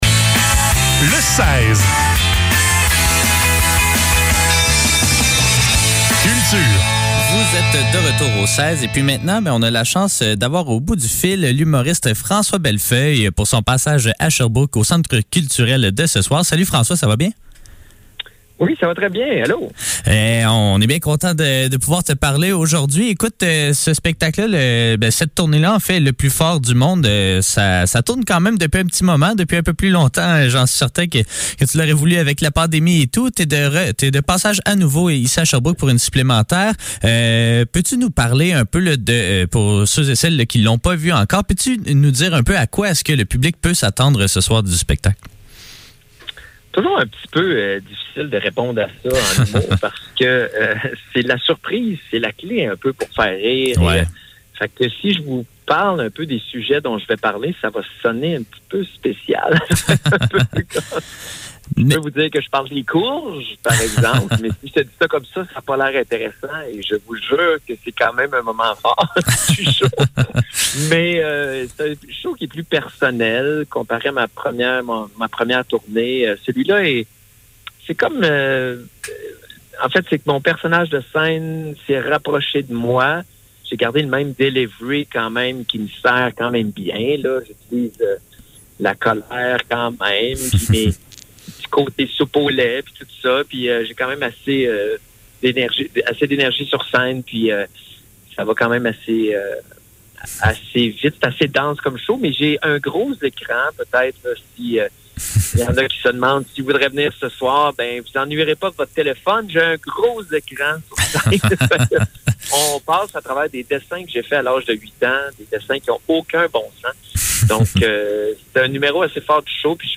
Le seize - Entrevue avec François Bellefeuille - 20 avril 2022